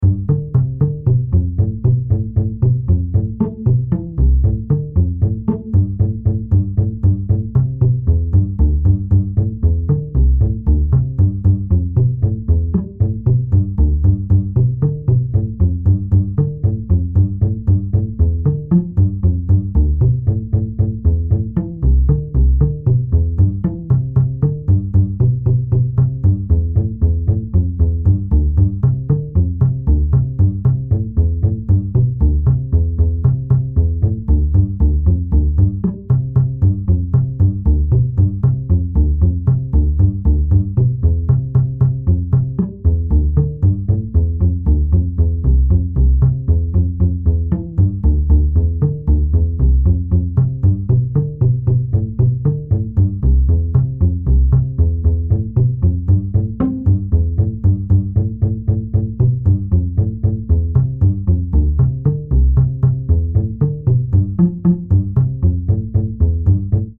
Sonificação da atividade sísmica em Portugal após o abalo de 4,7 na escala de Richter sentido em Lisboa no mês de fevereiro 2025.
4,1 Instrument: Double Bass Key: C Major Scale Range: 2 Octaves Track Tempo: 1x